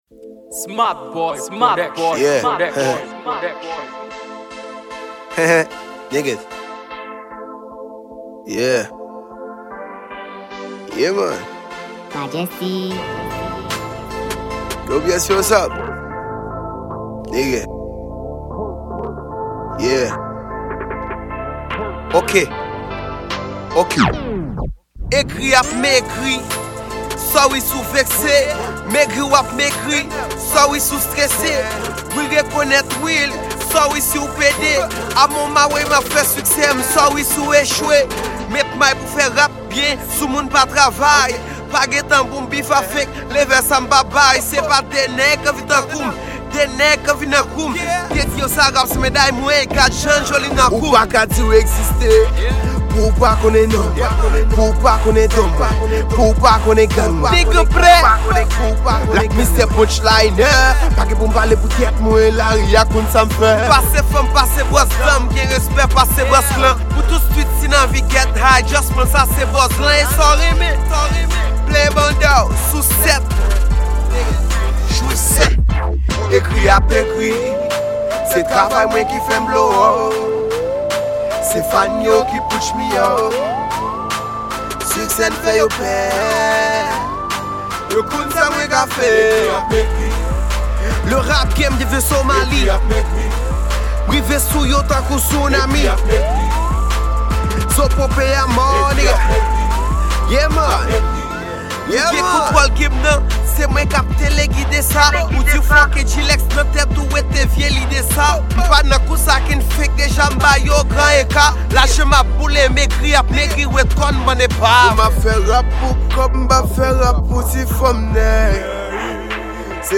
Genre: Rap Kreyol.